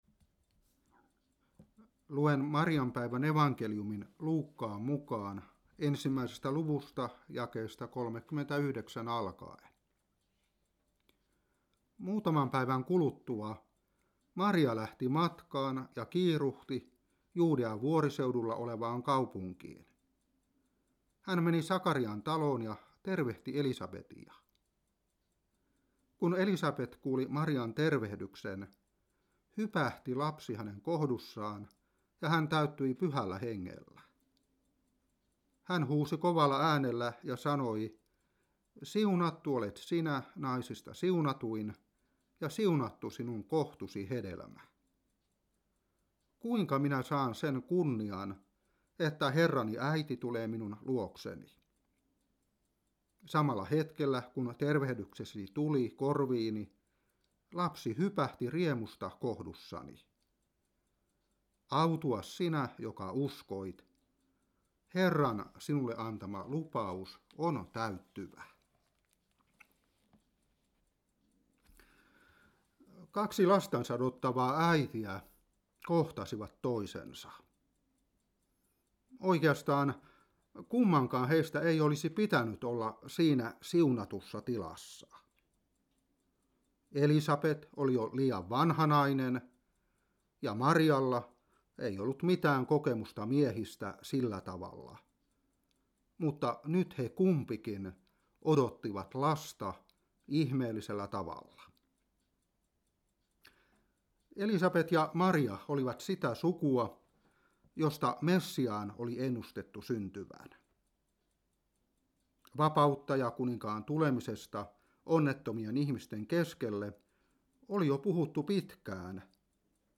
Saarna 2017-3.